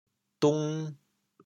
反切 端温 调: 抵 国际音标 [tun]